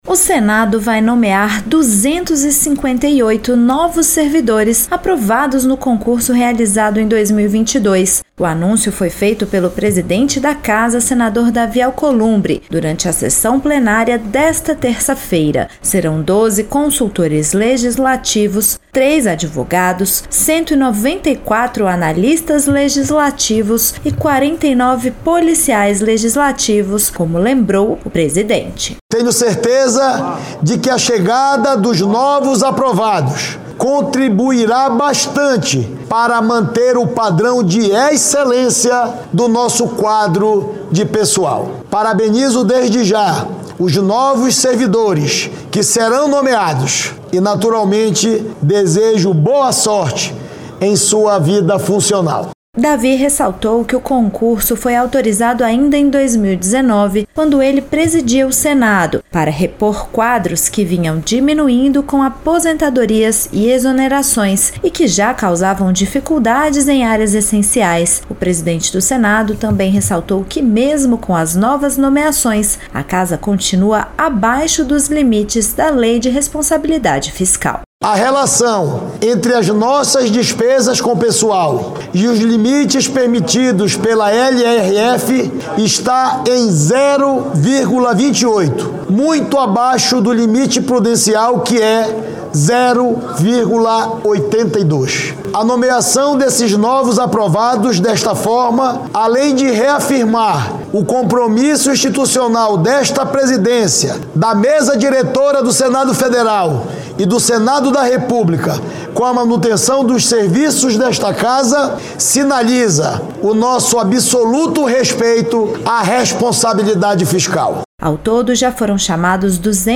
O Senado Federal vai nomear 258 novos servidores aprovados no concurso de 2022, conforme anunciado pelo presidente da Casa, Davi Alcolumbre, em sessão plenária desta terça-feira (30). As vagas serão distribuídas entre 194 analistas, 49 policiais legislativos, 12 consultores e três advogados.